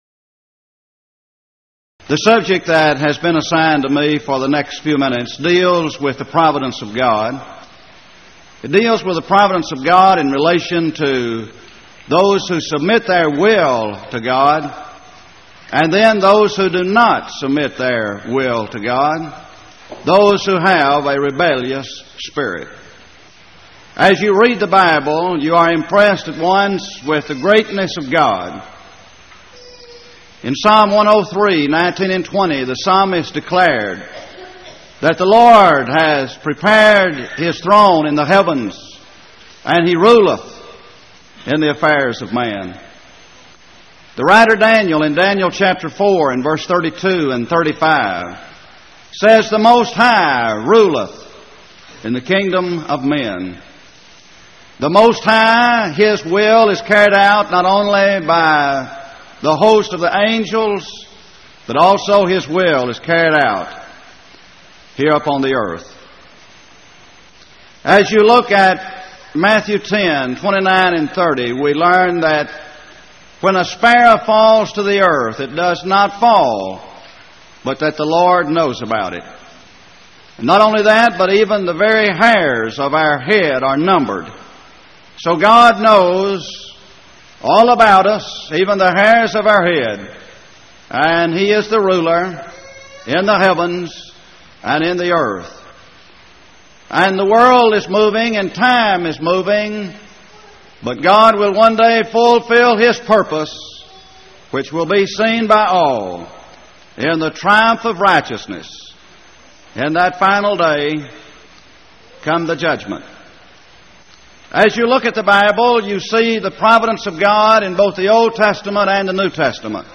Event: 1989 Power Lectures
lecture